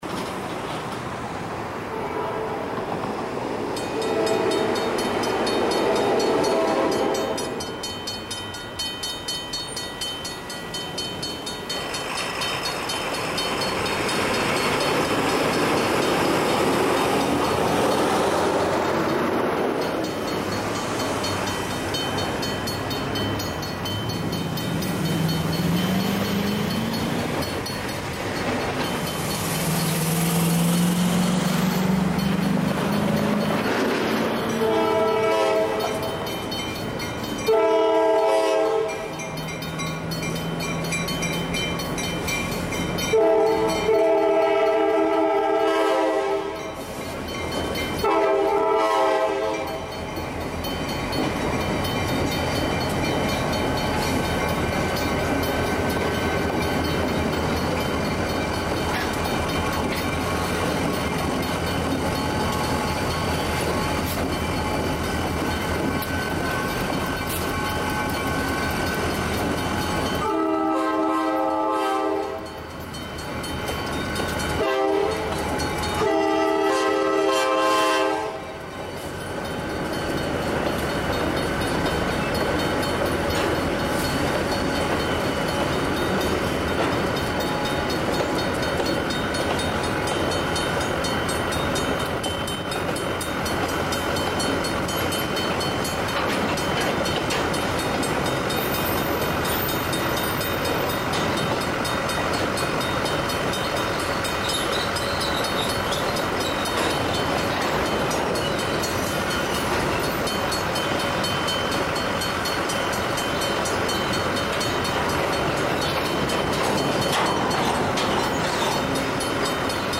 There is one Safetran mechanical bell on top of the northbound cantilever.
The train moved extremely slowly, and came to a complete stop three times. The crossing was blocked for over ten minutes.